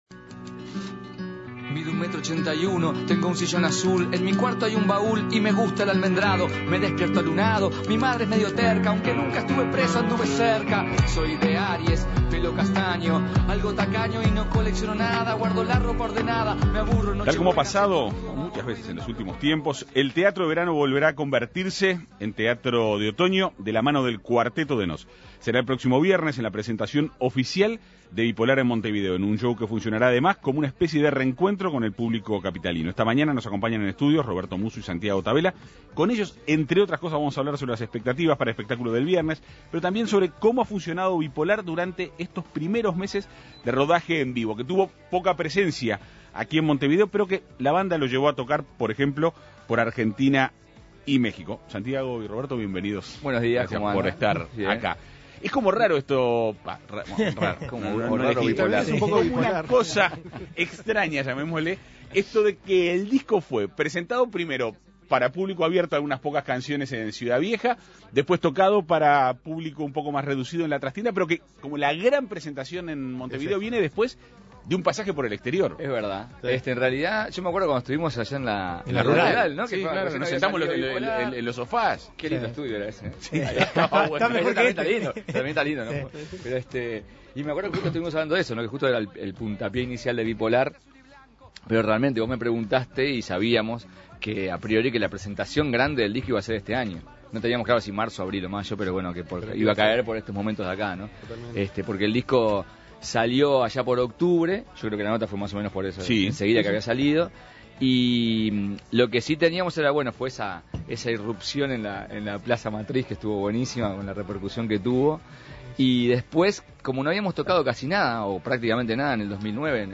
Para conocer detalles del espectáculo, En Perspectiva Segunda Mañana dialogó con Roberto Musso y Santiago Tavella, integrantes de la banda.